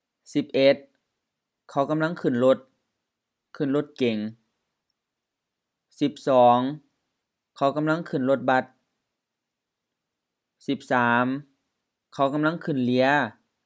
เลีย li:a HR เรือ boat
Notes: pronunciation: also realized as เลือ